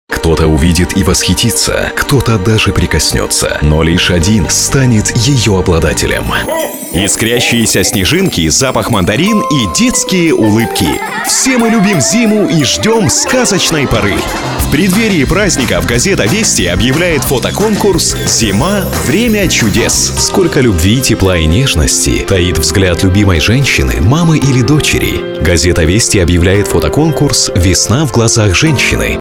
Тракт: Rode NT1A, TC Electronic Konnekt 8